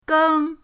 geng1.mp3